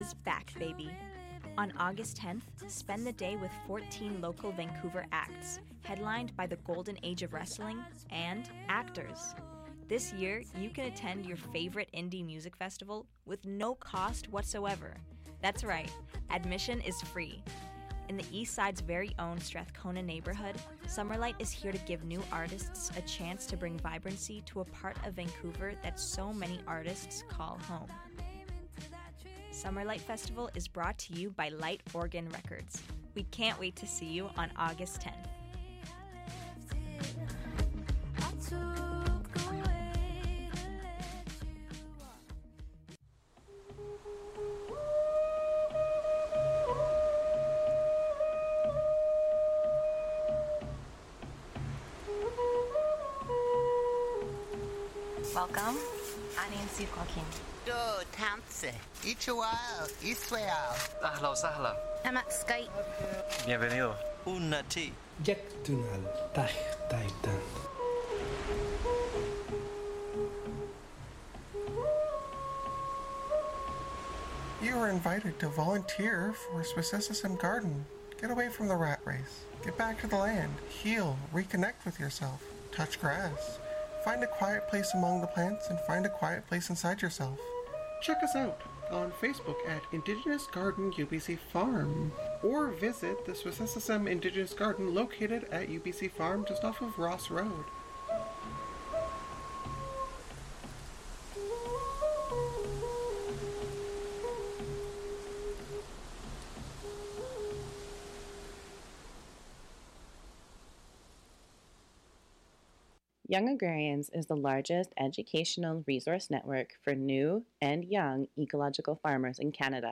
Concert Review